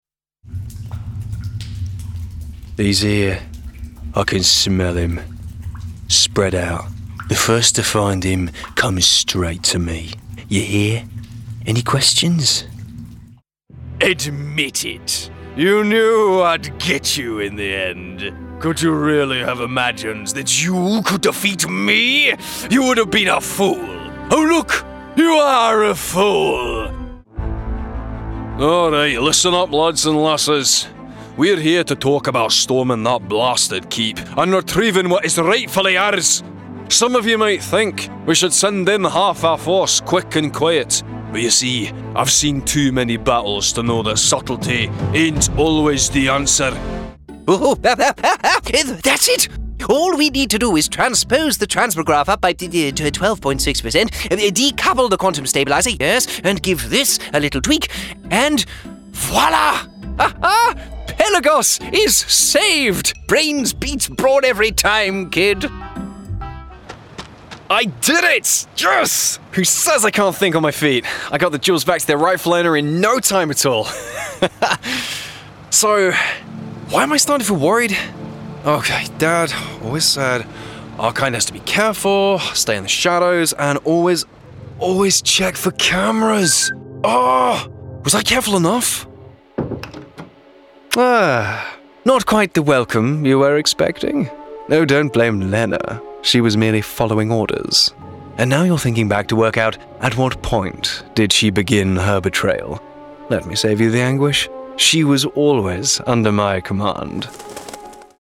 Comercial, Natural, Amable, Cálida, Versátil